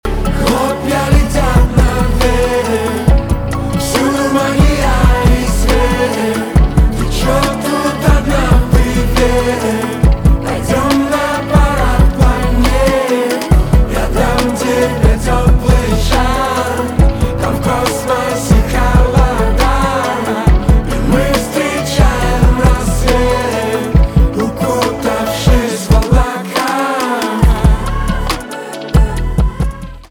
поп
романтические
битовые